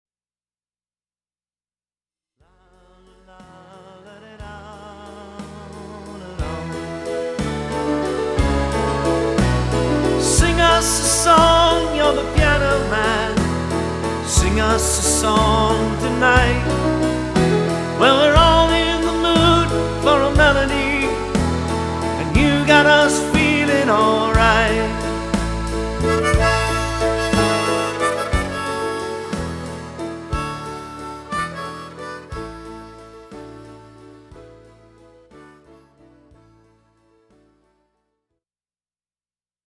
70's Music